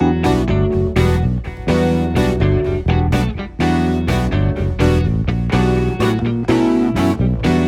32 Backing PT1.wav